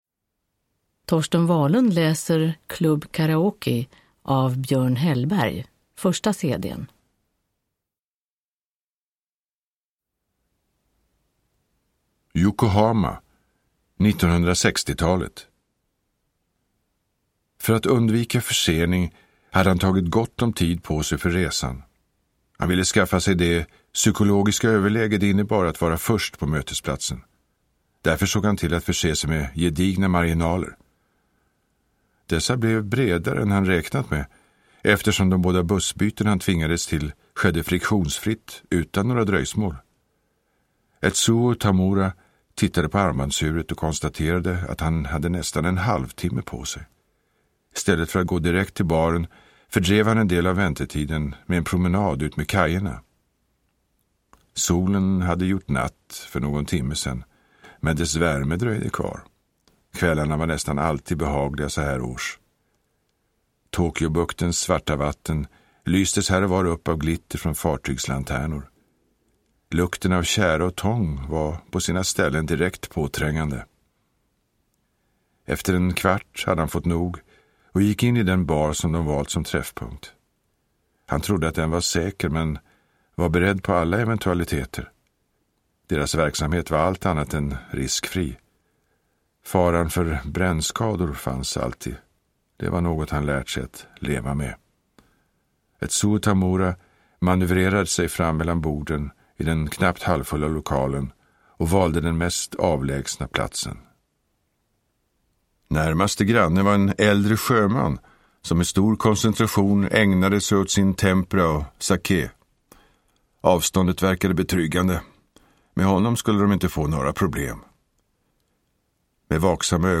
Produkttyp: Digitala böcker
Uppläsare: Torsten Wahlund